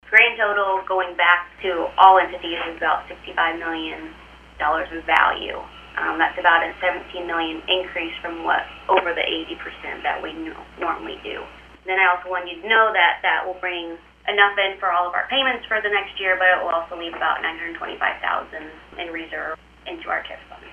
In other business, the Board received an update from Adair County Auditor Mandy Berg, with regard to TIF Values.